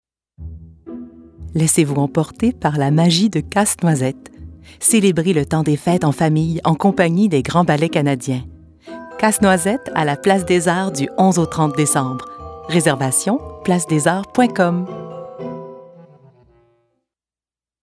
Publicité (Casse-Noisette) - FR